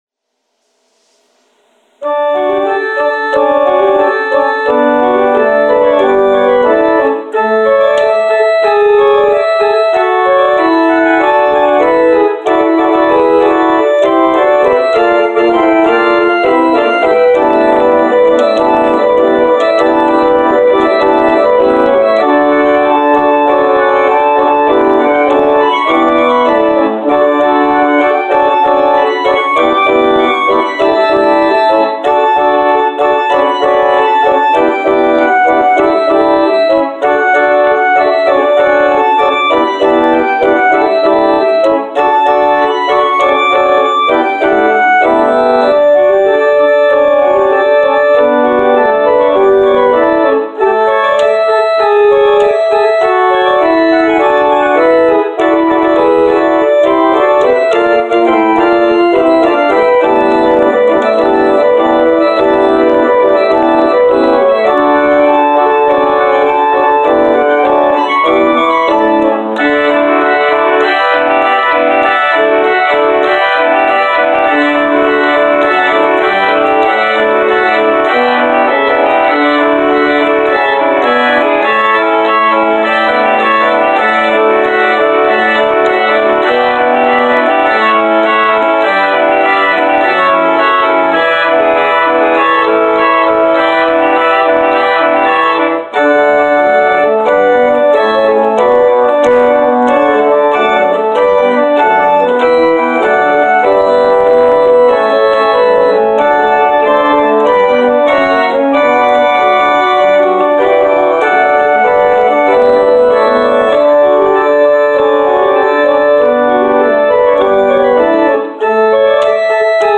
Postlude: “I Know that My Redeemer Lives” – John Hatton, arr. Jacob B. Weber